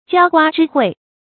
澆瓜之惠 注音： ㄐㄧㄠ ㄍㄨㄚ ㄓㄧ ㄏㄨㄟˋ 讀音讀法： 意思解釋： 比喻以德報怨，不因小事而引起紛爭。